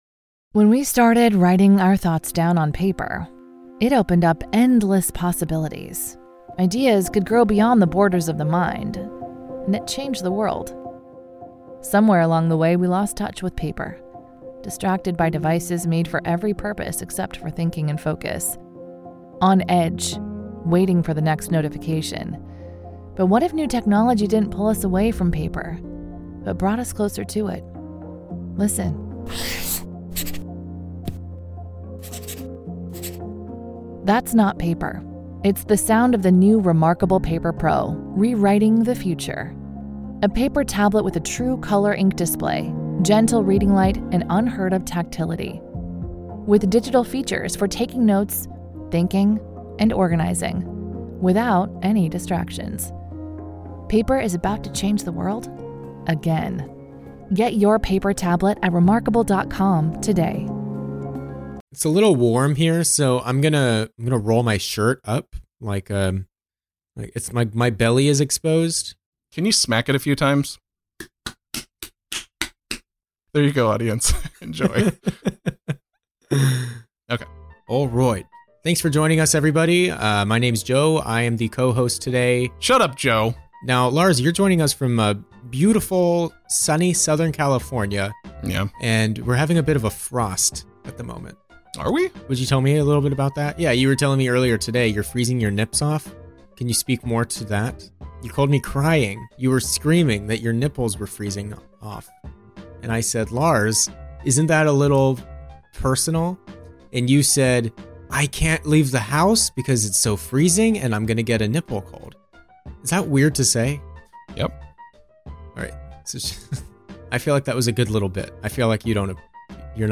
This episode was recorded live on our Discord.